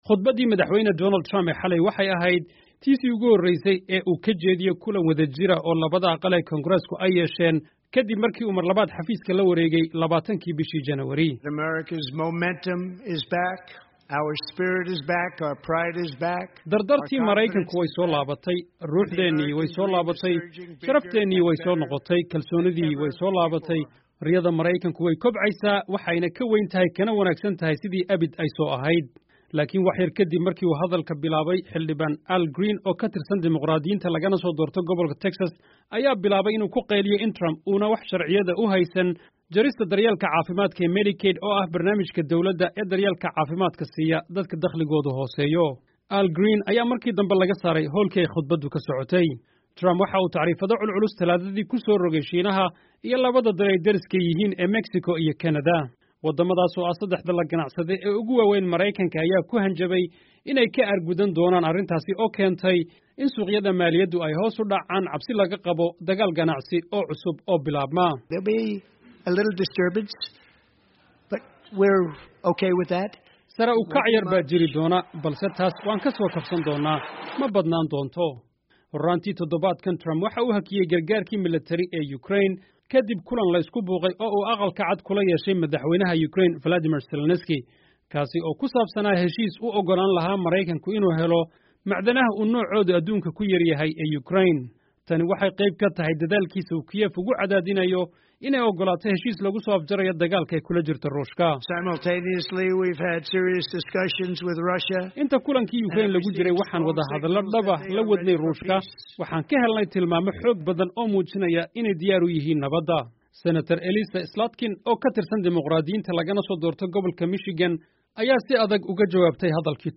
Trump Speech